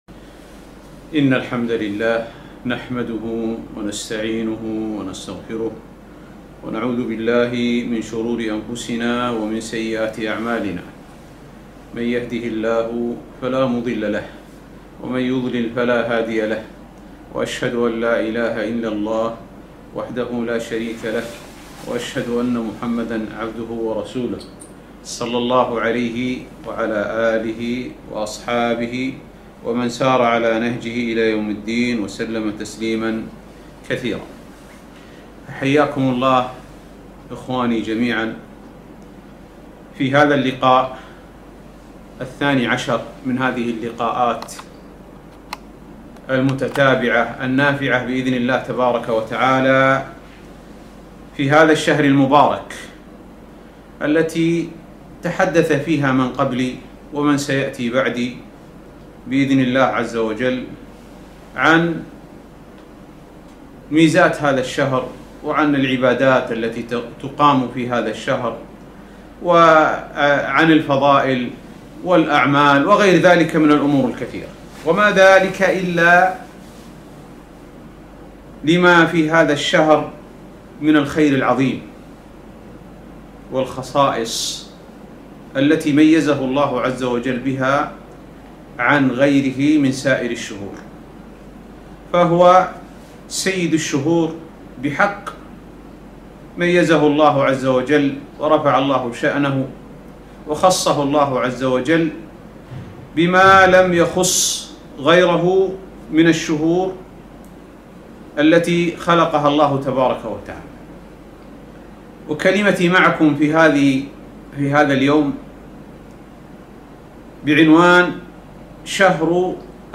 محاضرة - شهر الخير